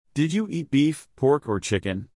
Note how the speaker’s intonation rises until “chicken” in the example: